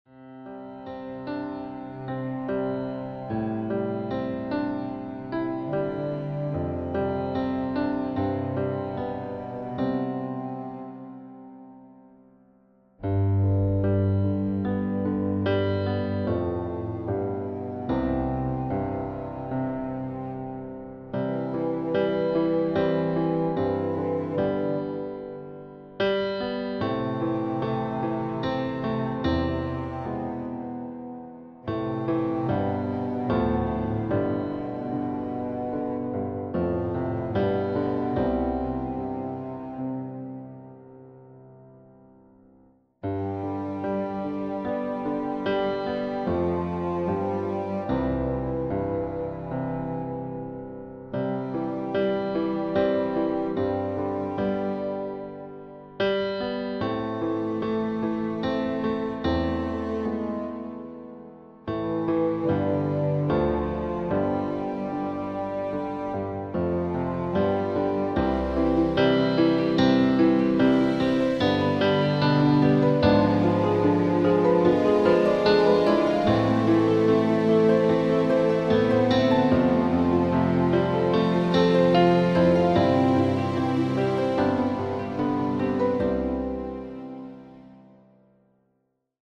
im Klavier Streicher Style
Klavier / Streicher